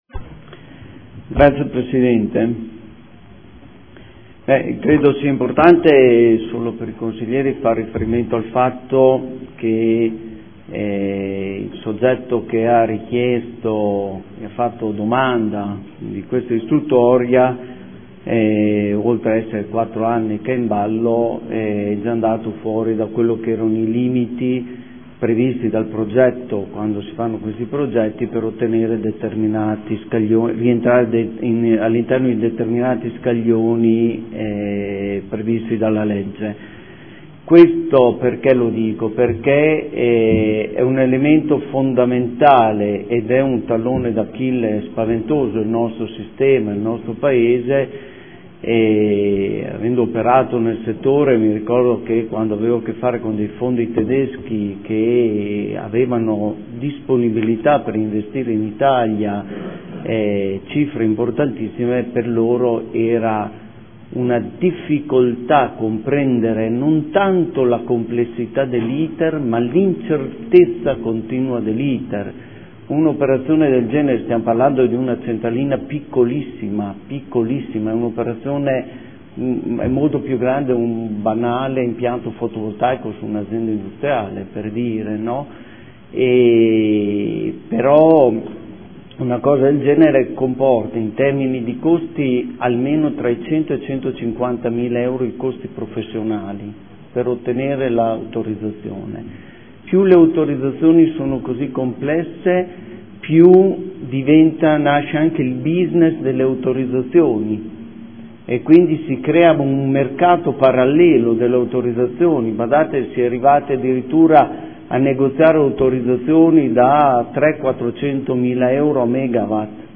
Antonio Montanini — Sito Audio Consiglio Comunale